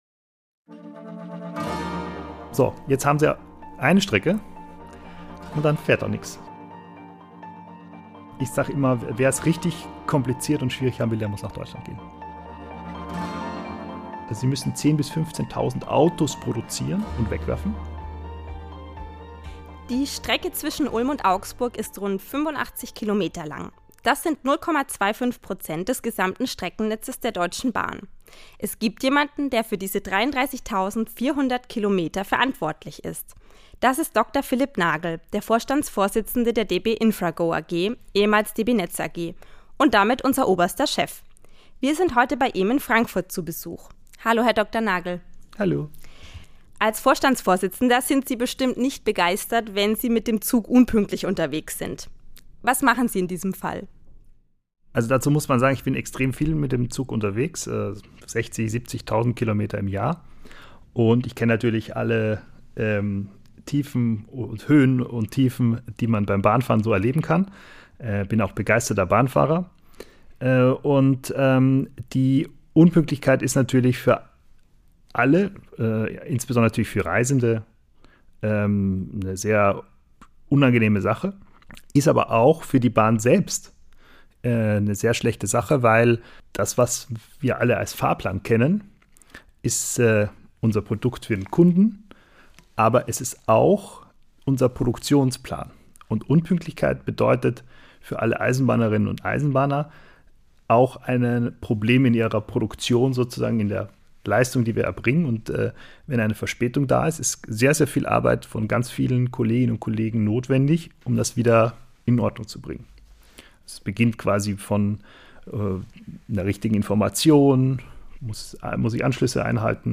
Ein Gespräch darüber, was das Schienennetz mit kleinen Häuschen und großen Villen zu tun hat, was die Bahn dem Technologiekonzern Apple voraus hat und was wir unseren Vorfahren schuldig sind.